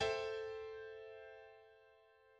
1  3 5  7 Mineur septième mineure A-7 Tonique[2]